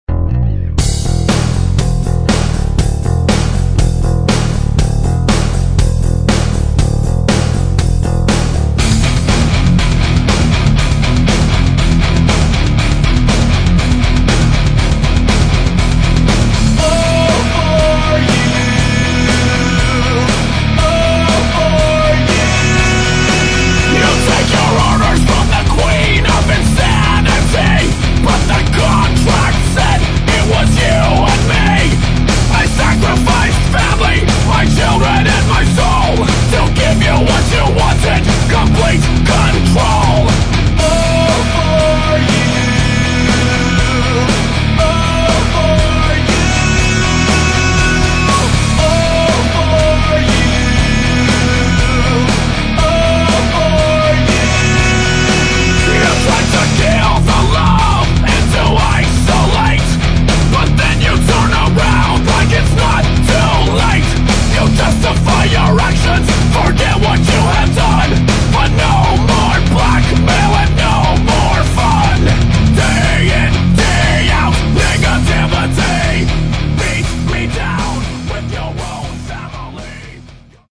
Metal
Эффекты, обработки, качественная в общем запись.